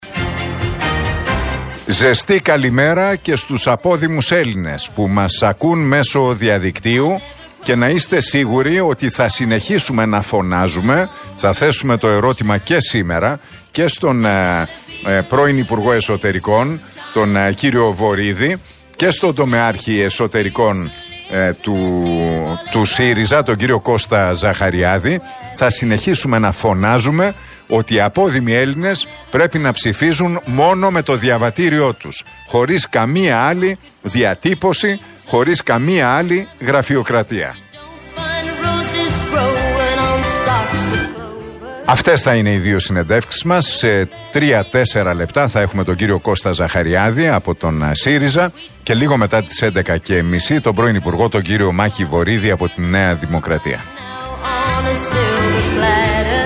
Το αίτημα των απόδημων Ελλήνων να ψηφίζουν στις εκλογές μόνο με το ελληνικό διαβατήριο είναι πιο επίκαιρο από ποτέ. Το θέμα ανέδειξε στην εκπομπή του στον Realfm 97,8 σήμερα (2/6) ο Νίκος Χατζηνικολάου.